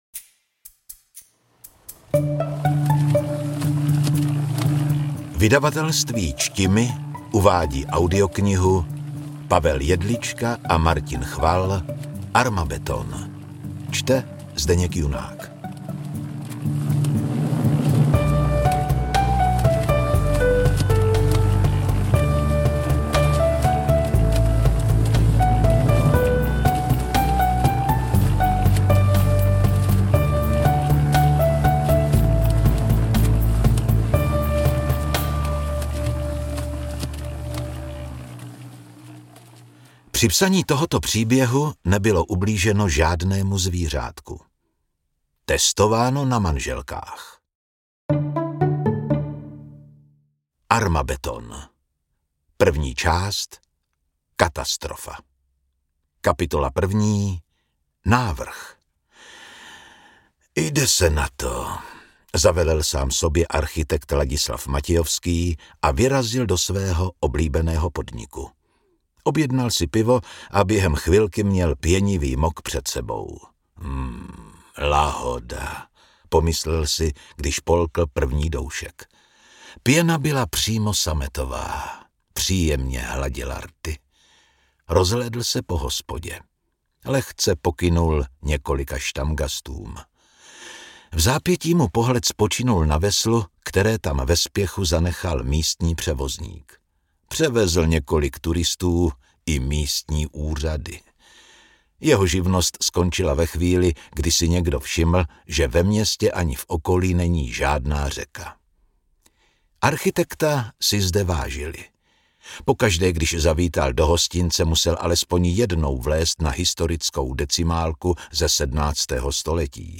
AudioKniha ke stažení, 39 x mp3, délka 5 hod. 54 min., velikost 323,6 MB, česky